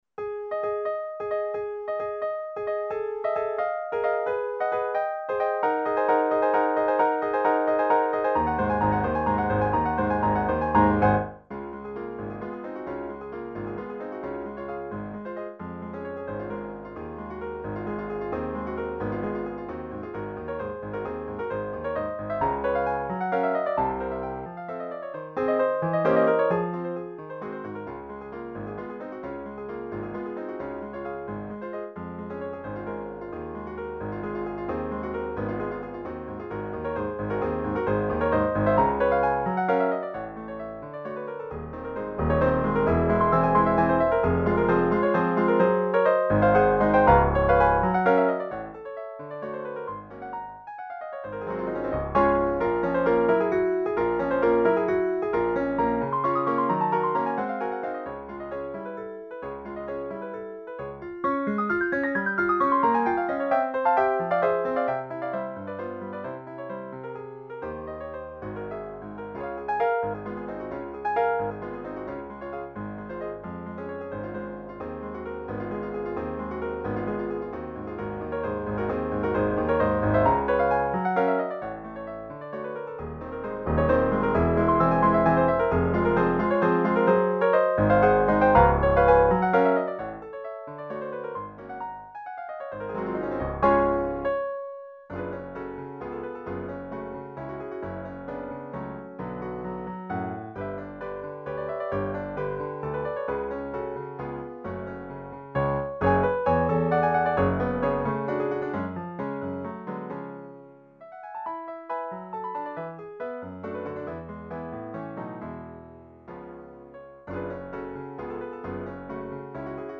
Valse-Caprice, Op.33 Piano version
piano
Style: Classical